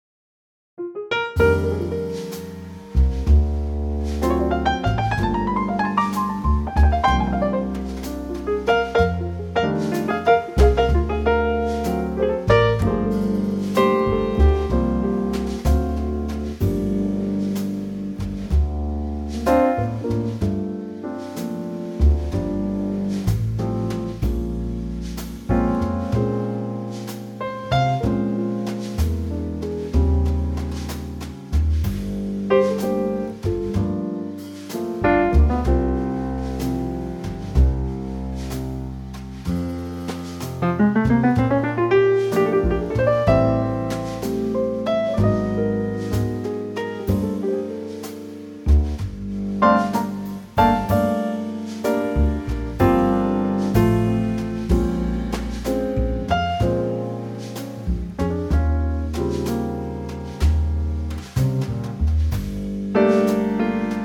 key - F - vocal range - C to Eb
Wonderful Trio arrangement